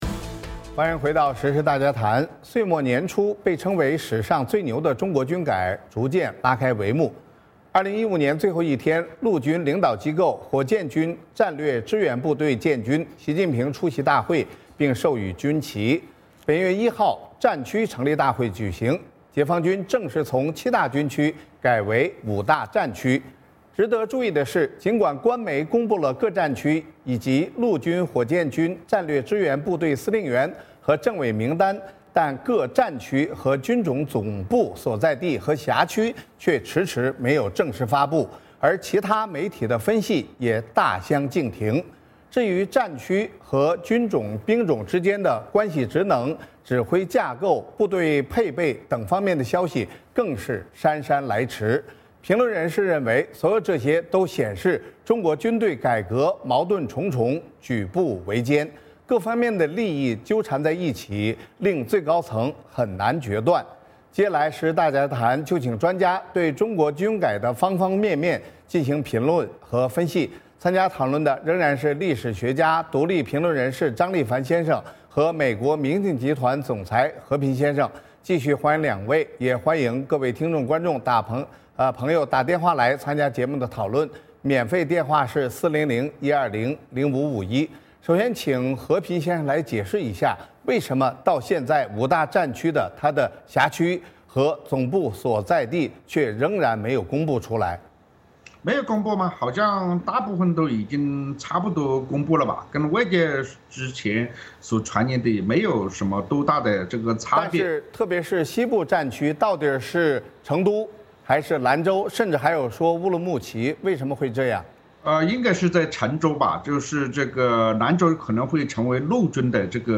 今天的时事大家谈请专家对中国军改的方方面面进行评论和分析。